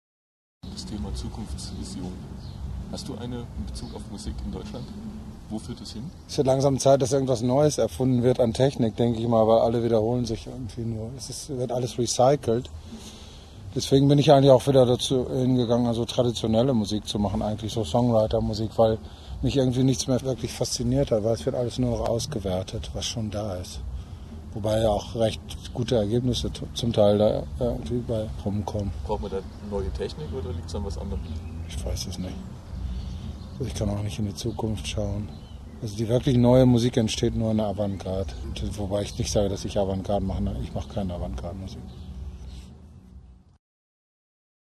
MP3 - INTERVIEW- AUSSCHNITT